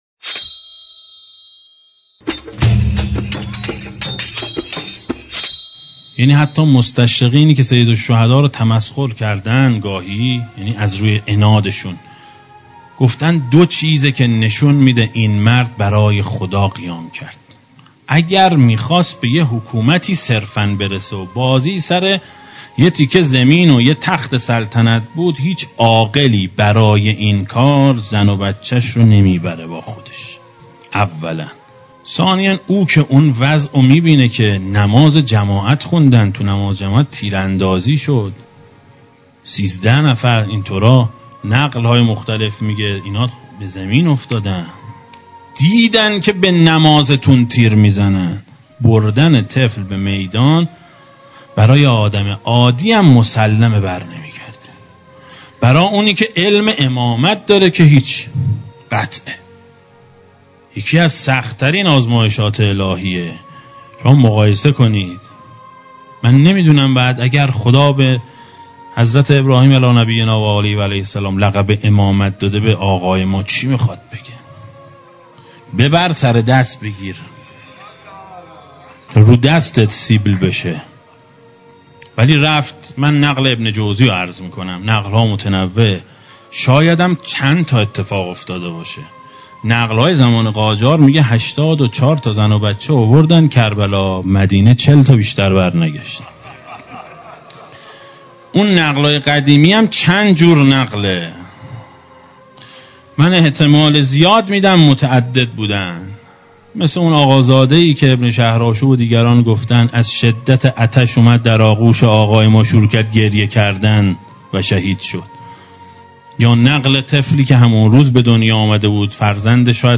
روضه شب هفتم محرم سال 1394
دسته: امام حسین علیه السلام, روضه های اهل بیت علیهم السلام, سخنرانی ها